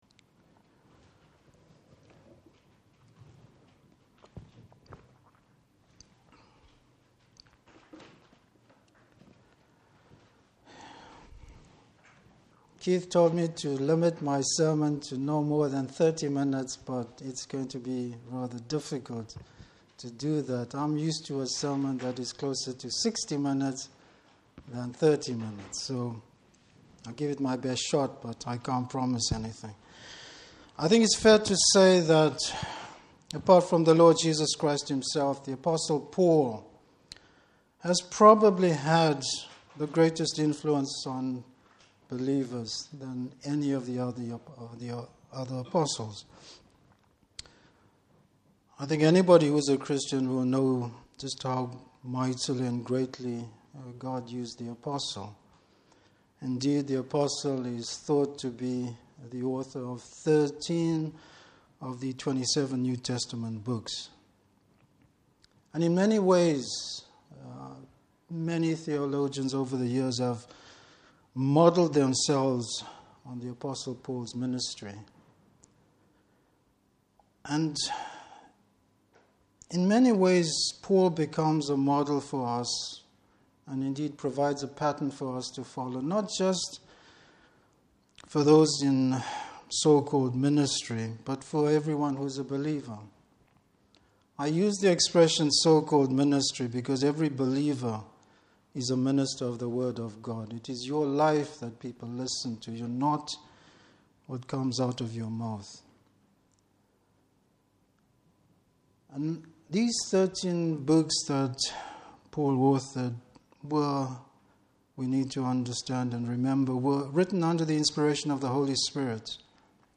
Service Type: Morning Service Bible Text: 1 Thessalonians 4:9-12.